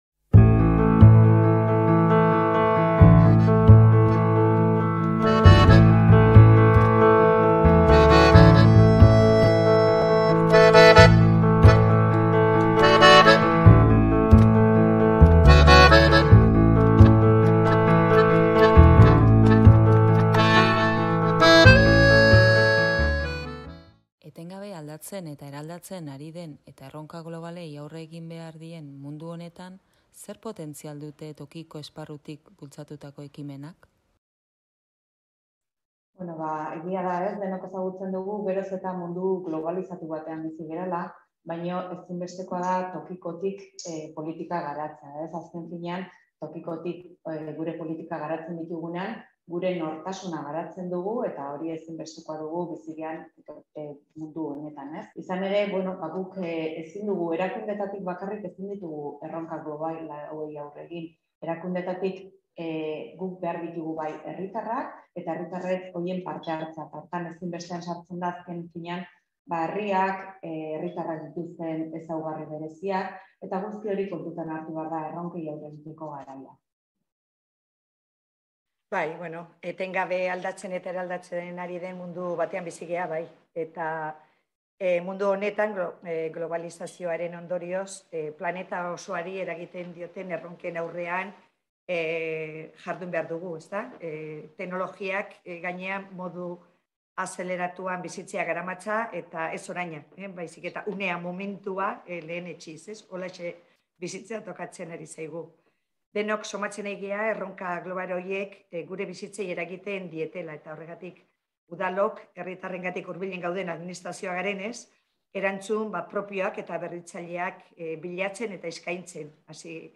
Bere interesa eta eragina baloratzeko, Solasaldiak atalean bi udalerri hauetako alkateak bildu ditu hil honetan: Leire Artola, Beasaingoa eta Koldobike Olabide, Legazpikoa.